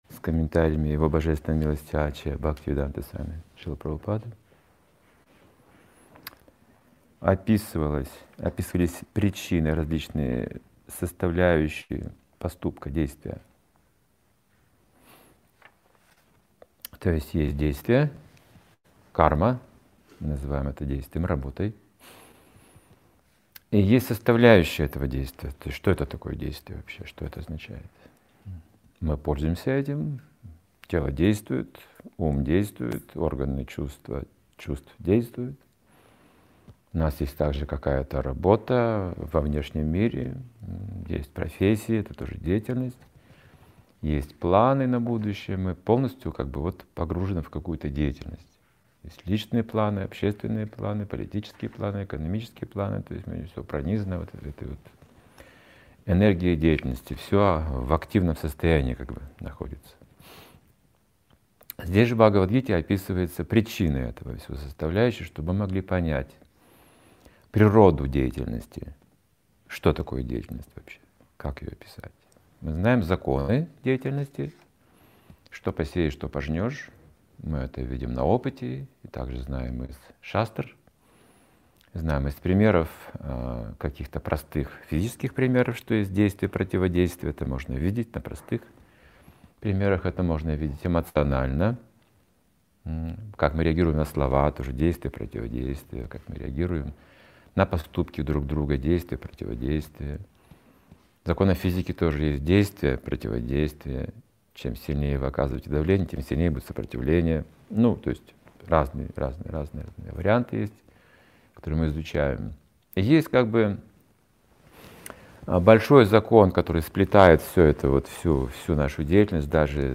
Вебинар для тех